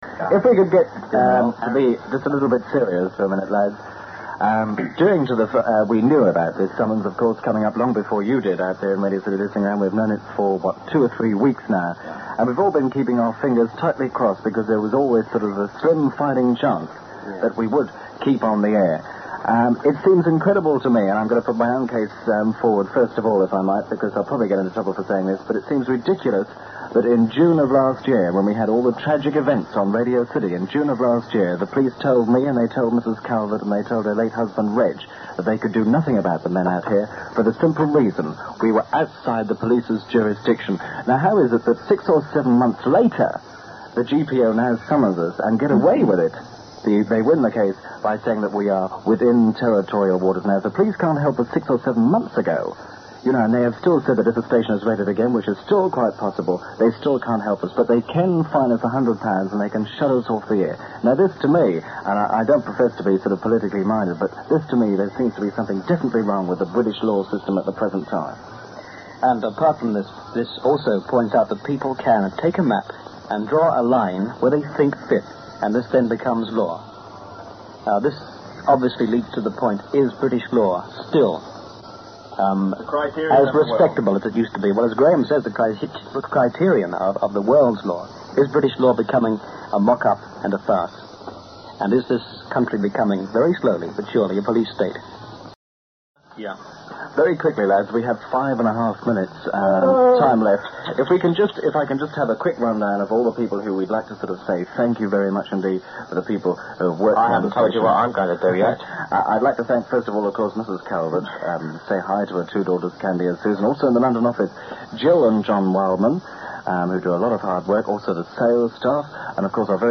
The Government was delighted when it realised it could suggest the towers were in territorial waters after all, and the station closed at midnight on 8 February 1967. Hear again, here, the anger and frustration - and then the sadness - of the last few minutes.